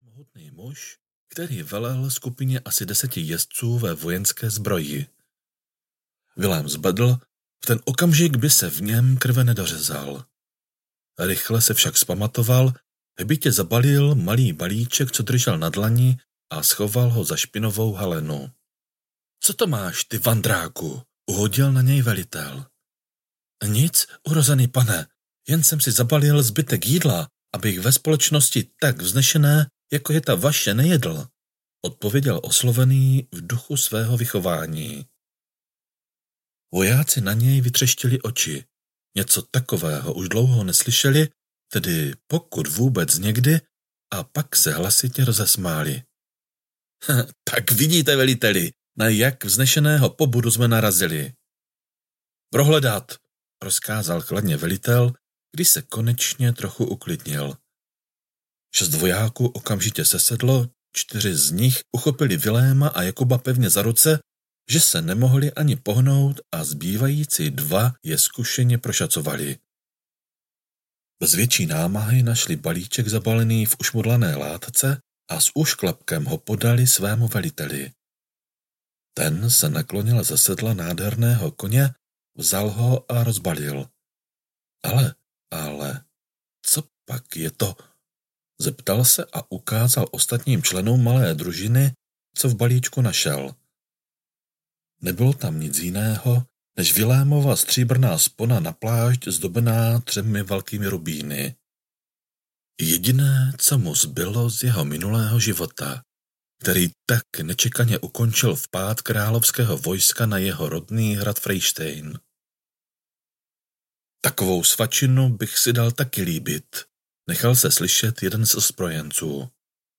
Vražda nepočestné panny audiokniha
Ukázka z knihy
vrazda-nepocestne-panny-audiokniha